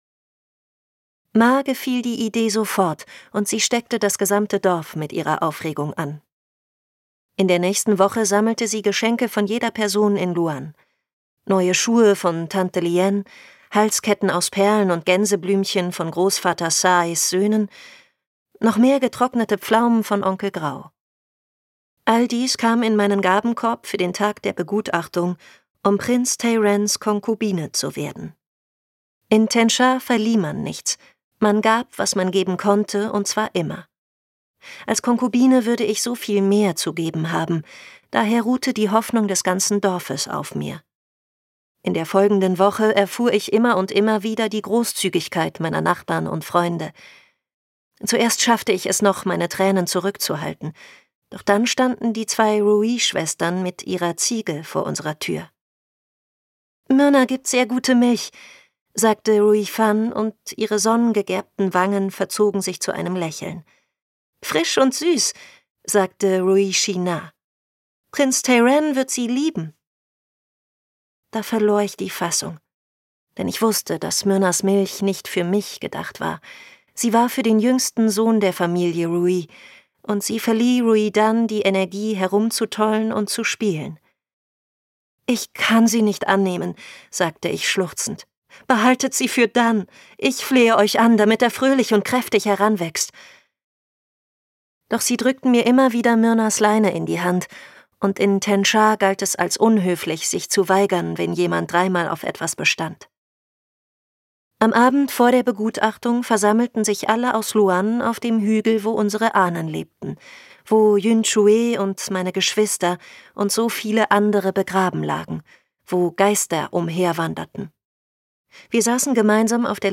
Hörbuch E-Book Print